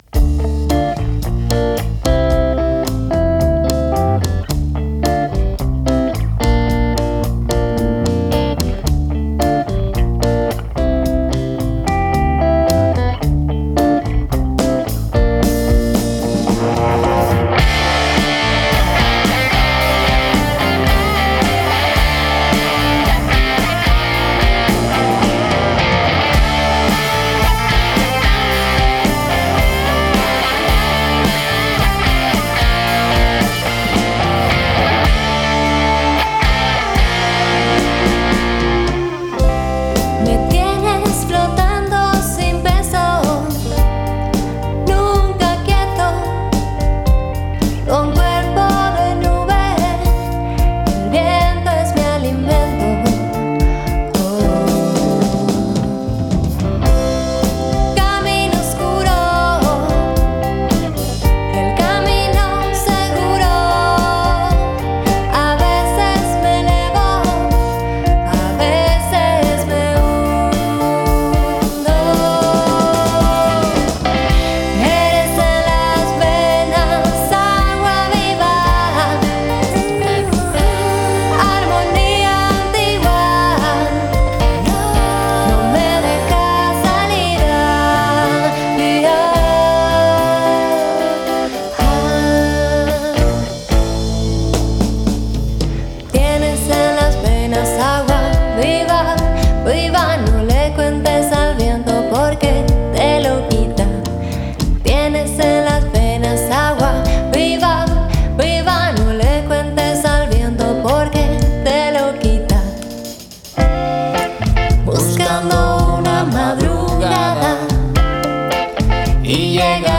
Banda, Fusión, Disco, Producción musical, Álbum